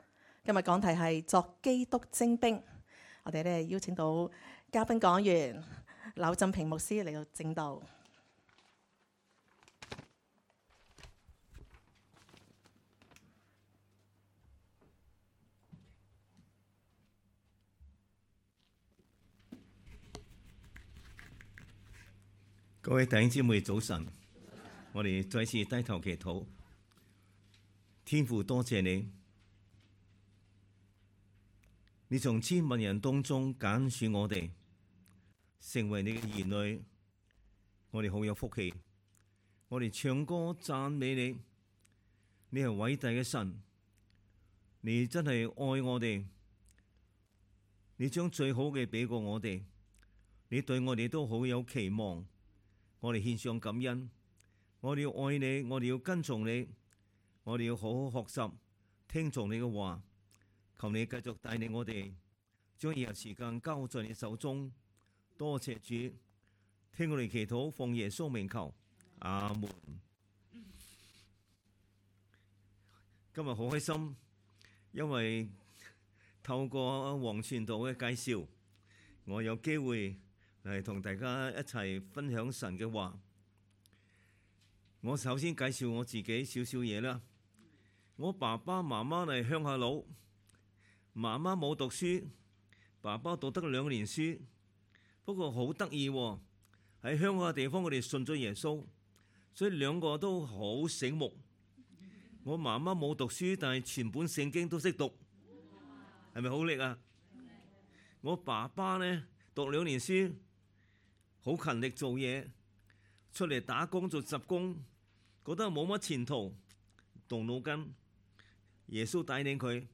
2023 年 7 月 15 日 及 16 日崇拜
講道 ：作基督精兵